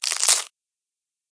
PixelPerfectionCE/assets/minecraft/sounds/mob/silverfish/step3.ogg at mc116
step3.ogg